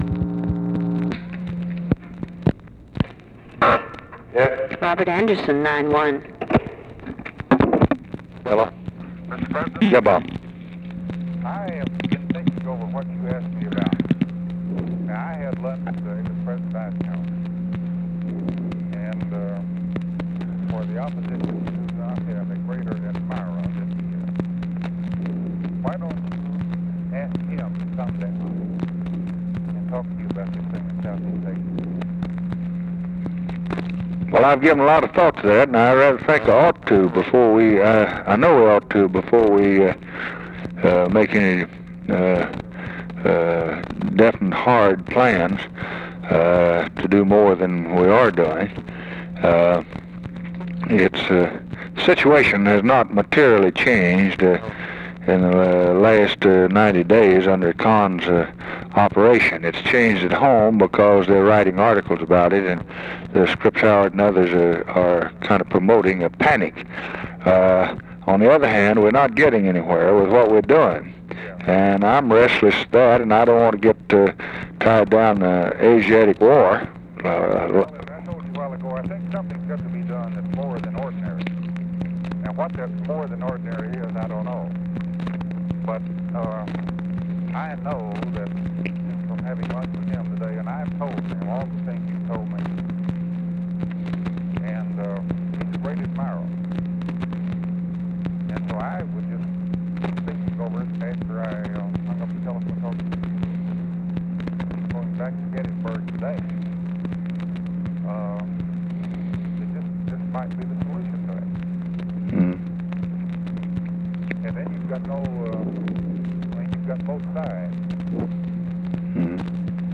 Conversation with ROBERT ANDERSON, June 2, 1964
Secret White House Tapes